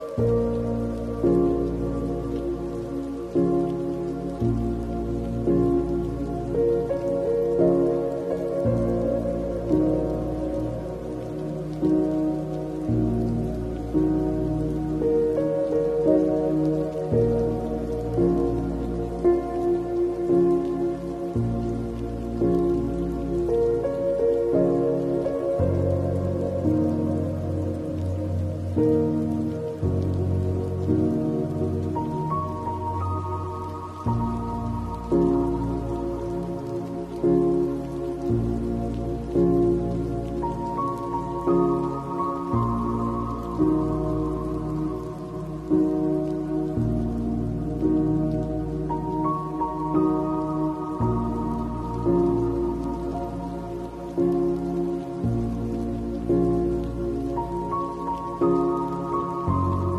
Translink plays high pitched screening sound effects free download
Translink plays high pitched screening noise at SkyTrain station